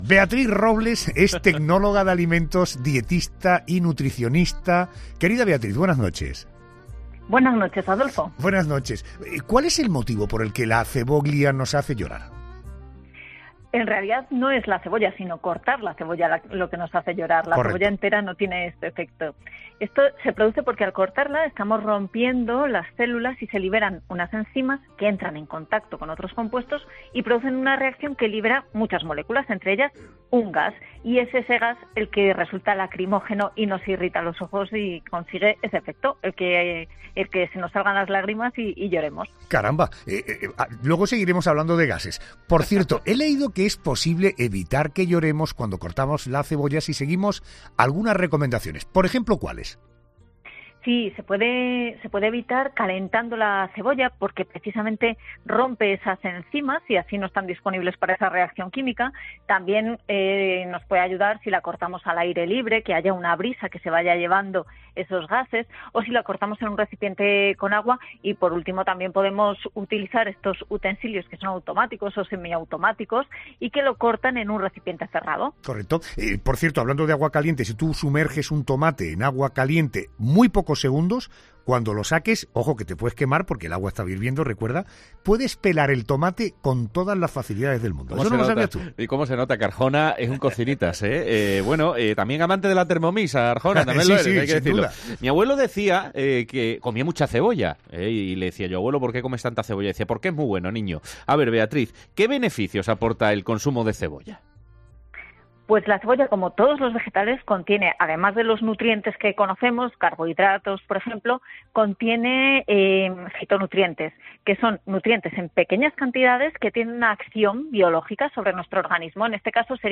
charla con una experta sobre todas las curiosidades que encierra la cebolla, un ingrediente que algunos aman y otros, no tanto